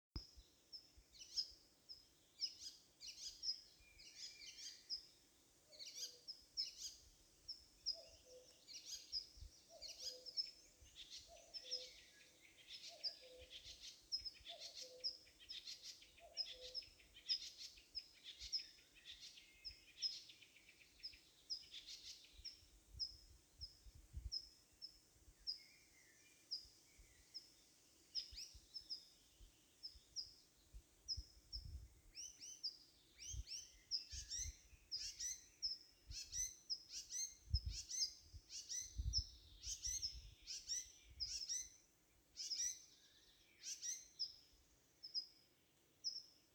пересмешка, Hippolais icterina
Administratīvā teritorijaGarkalnes novads
СтатусСлышен голос, крики